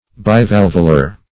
bivalvular - definition of bivalvular - synonyms, pronunciation, spelling from Free Dictionary
Bivalvular \Bi*val"vu*lar\, a. Having two valves.